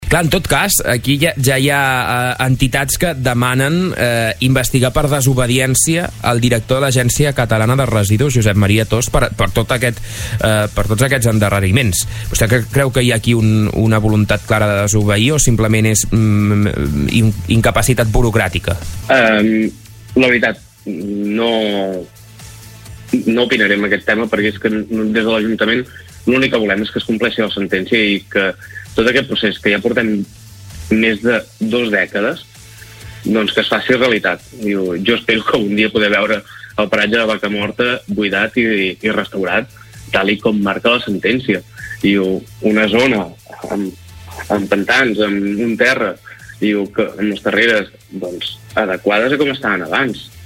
Supermatí - entrevistes
Al superat del 15 d’abril vam parlar amb Dani Encinas, alcalde de Cruïlles, Monells i Sant Sadurní de l’Heura sobre aquest tema.